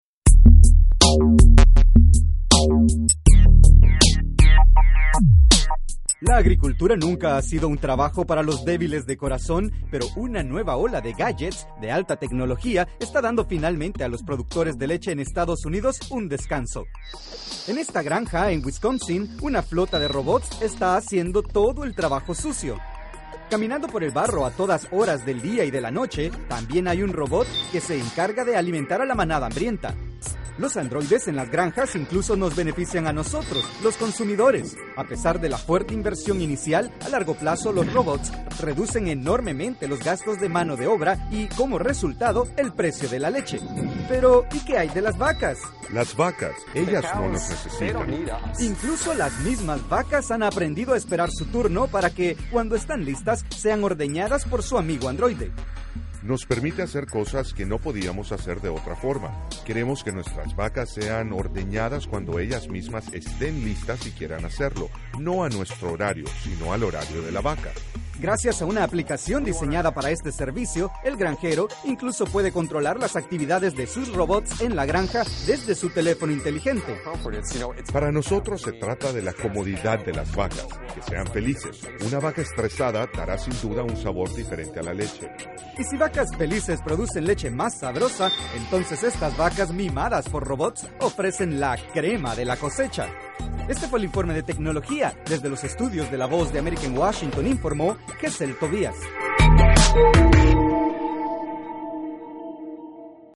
Las granjas de Wisconsin y Maryland están reemplazando a sus granjeros con robots de alta tecnología que limpia, alimenta y ordeña a las vacas. Desde los estudios de la Voz de América en Washington nos informa